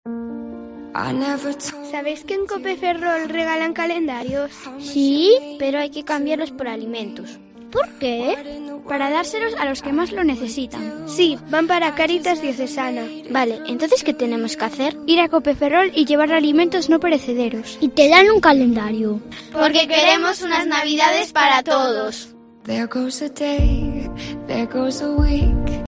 Cuña promocional "Navidades para todos"